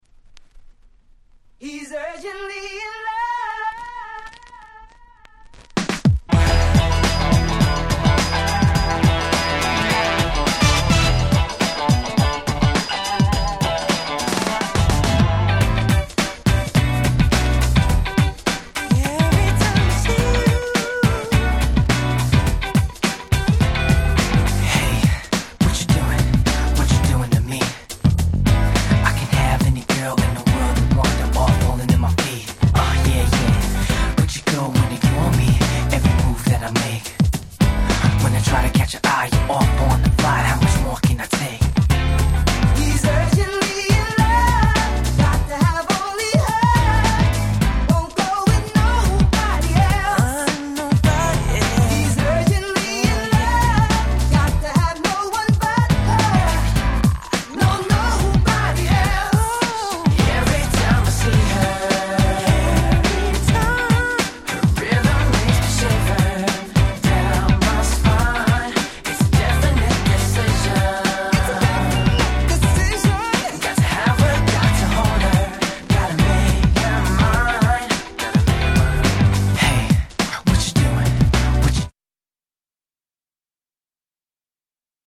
キャッチーR&B Classic !!